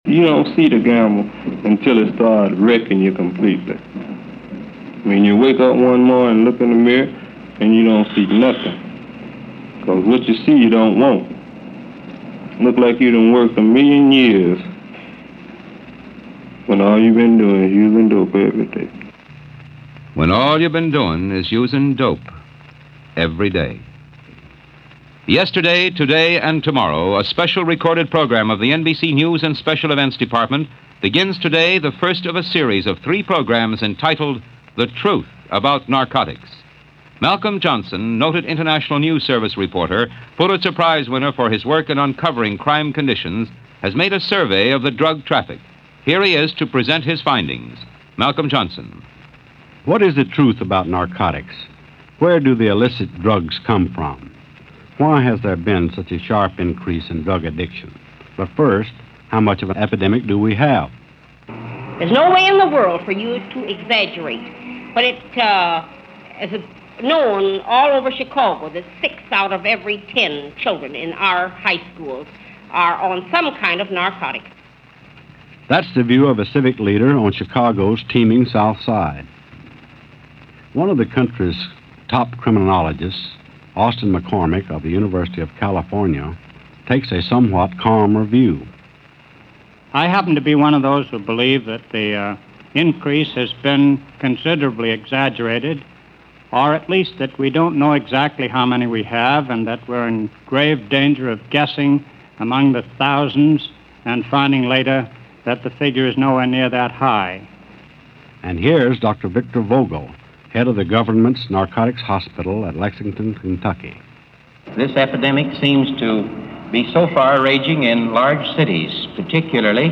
America's Little Dope Problem - Narcotics In the 1950's - 1951 documentary about the problems of drugs and drug addiction in America.